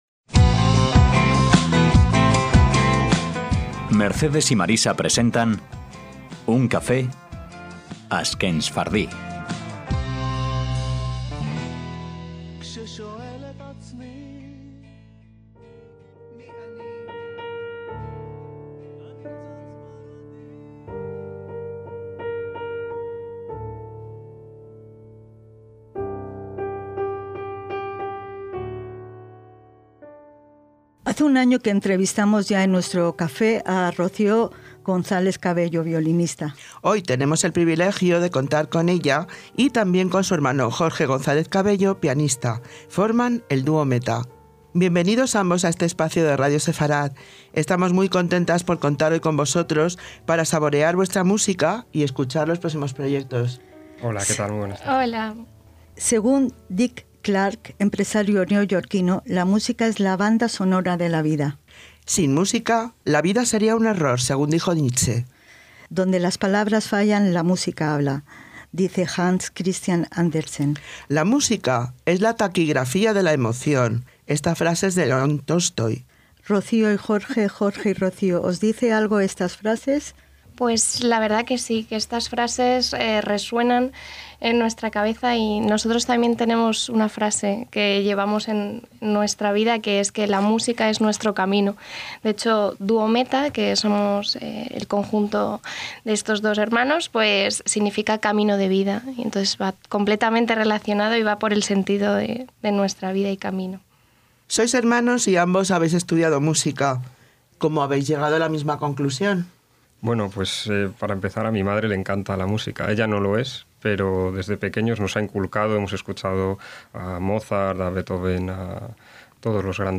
ASHKENSFARDÍ - En nuestro espacio vamos a tener una primicia: el dúo Metha (violín y piano) nos presentan un nuevo trabajo vinculado a la música judía que próximamente verá la luz.